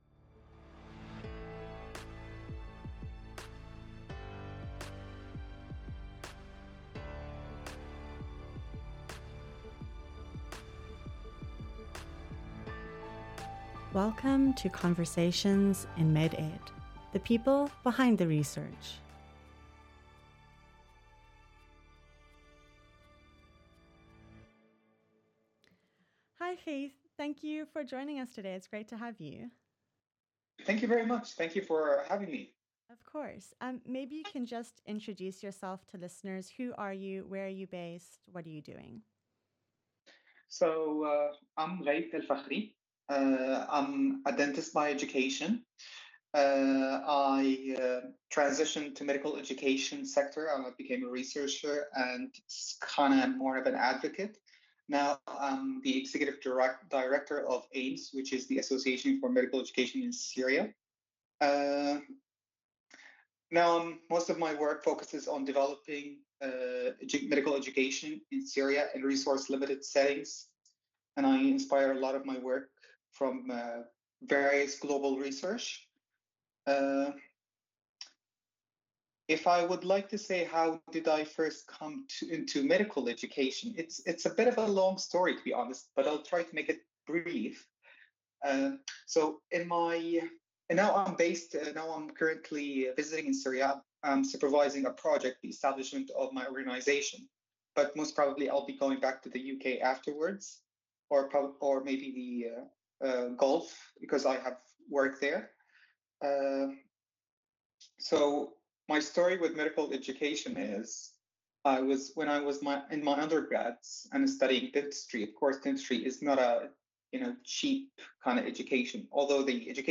a dentist turned educationalist